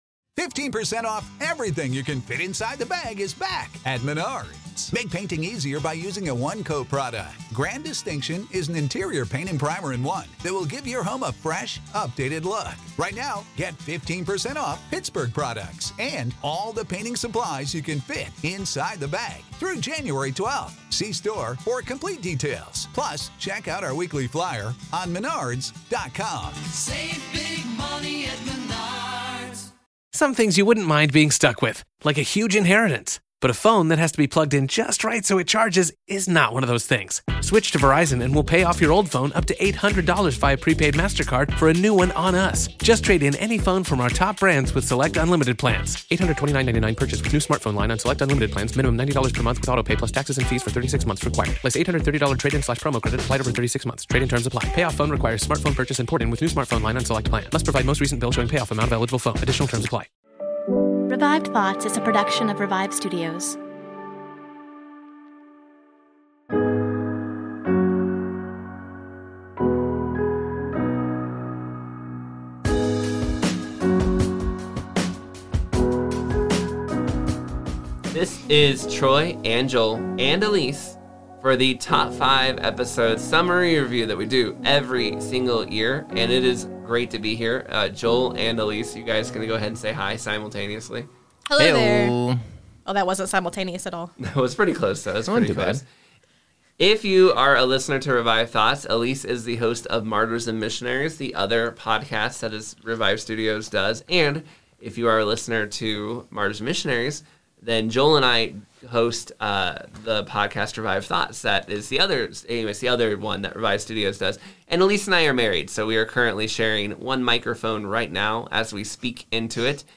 We are bringing history's greatest sermons back to life! Sermons from famous preachers like Charles Spurgeon, DL Moody, John Calvin, and many more.
Each episode features a 5-10 minute backstory on who the preacher is to better understand the sermon's context.